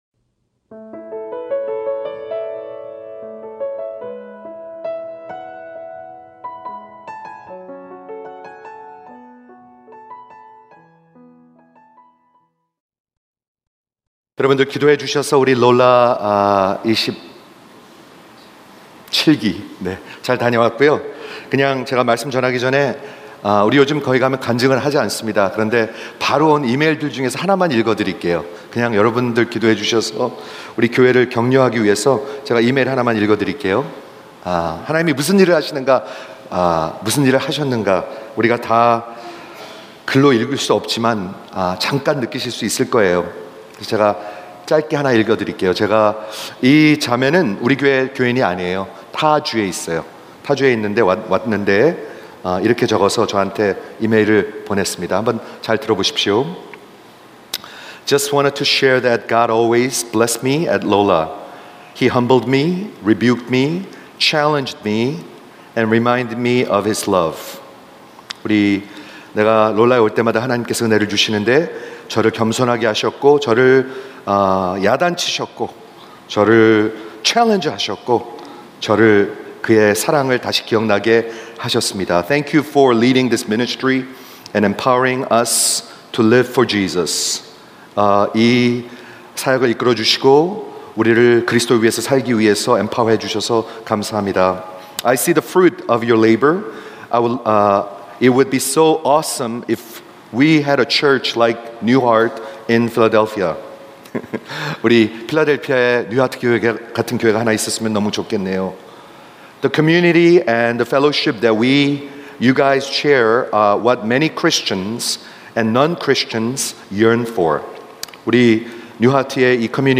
Christianity Expounded (7) 로마서 설교 시리즈: 복음 – 은혜로 값없이 의롭다 하심을 얻음 | 로마서3:21-26